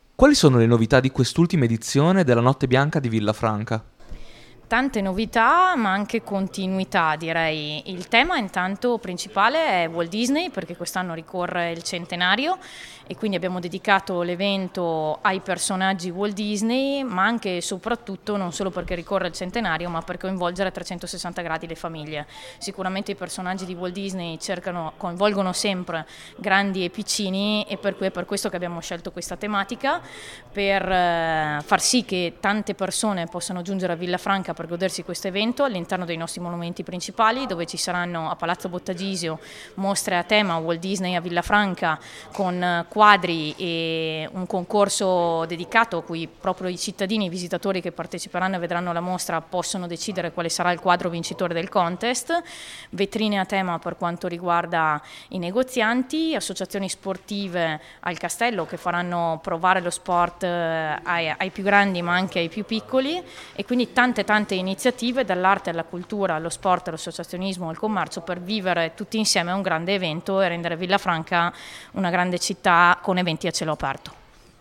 Ecco le dichiarazioni raccolte nella giornata di presentazione:
Claudia Barbera assessore alla Cultura, Eventi e Manifestazioni: